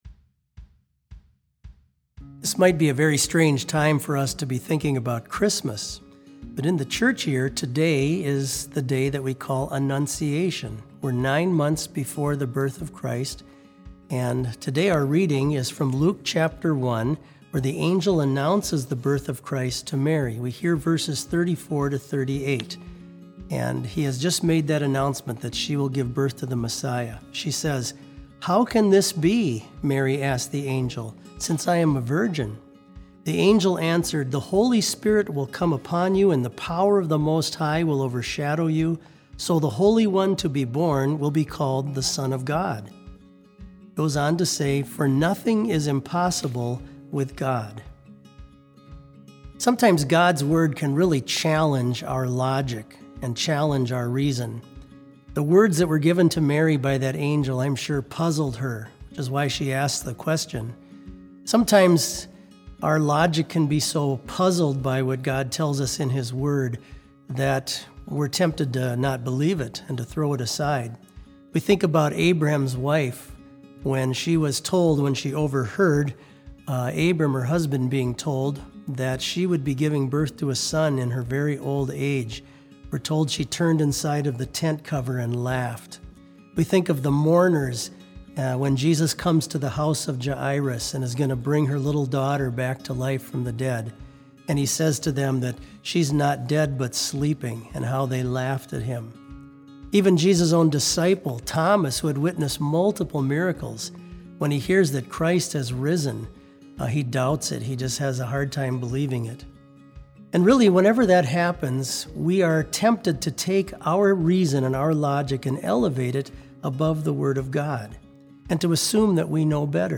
Complete Service
• Devotion
This Special Service was held in Trinity Chapel at Bethany Lutheran College on Wednesday, March 25, 2020, at 10 a.m. Page and hymn numbers are from the Evangelical Lutheran Hymnary.